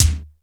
BIG BD 2.wav